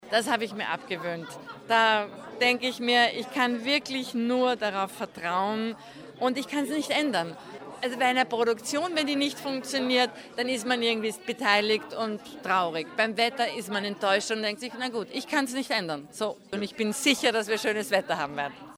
o-ton_bregenzer-festspiele_pressetag-i2024_news.mp3